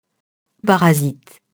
parasite [parazit]